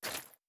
goodcircle/IdleRPG2024 - Assets/_8Sound/Grenade Sound FX/Different sounds/Throw8.wav at 3ec0361e0dac11c91f65ddc613164fe25bdacf65 - IdleRPG2024 - GoodCircle
Throw8.wav